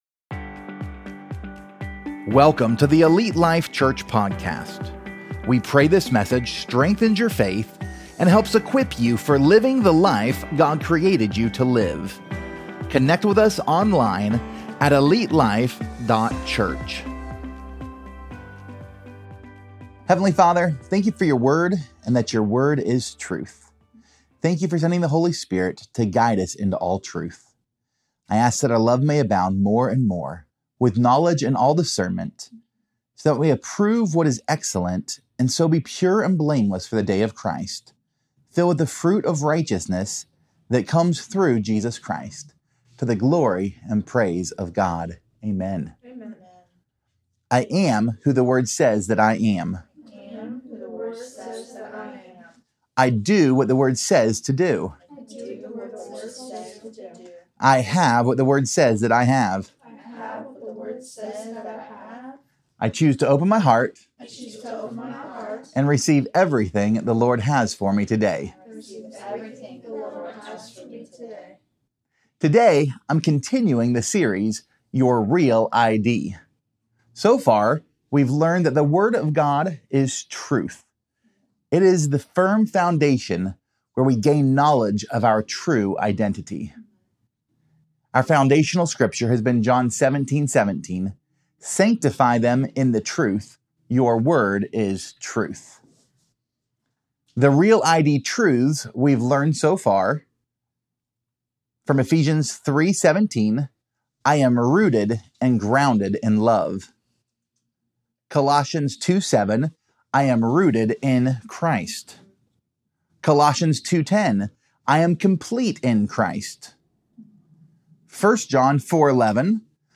Pt 06: The Masterpiece! | Your REAL ID Sermon Series